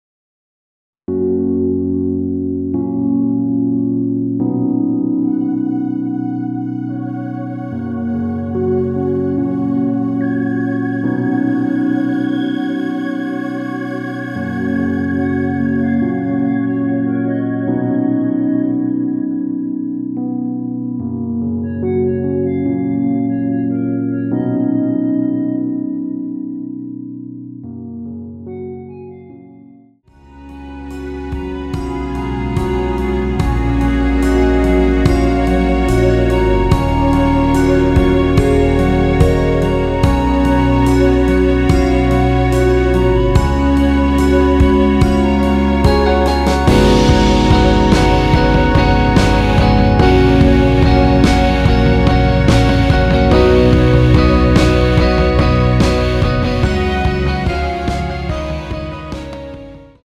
원키에서(+4)올린 멜로디 포함된 MR입니다.(미리듣기 확인)
F#
앞부분30초, 뒷부분30초씩 편집해서 올려 드리고 있습니다.
중간에 음이 끈어지고 다시 나오는 이유는